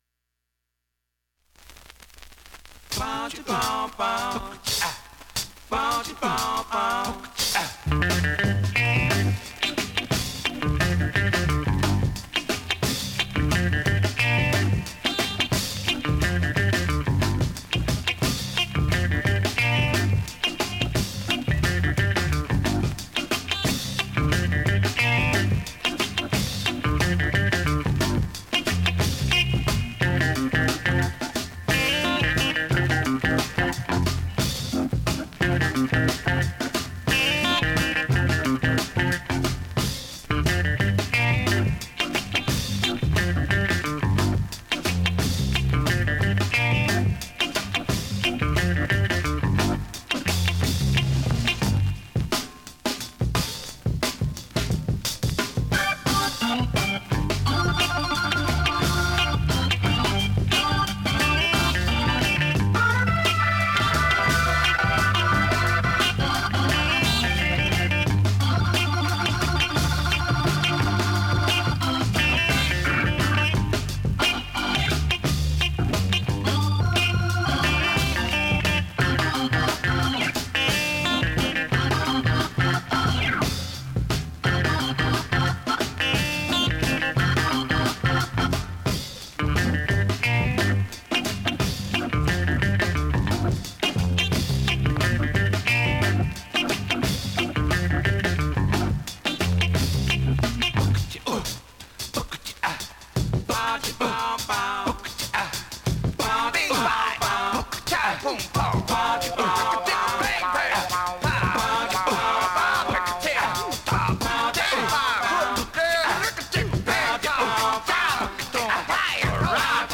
現物の試聴（両面すべて録音時間６分）できます。